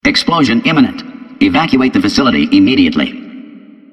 reactor_alert_3.ogg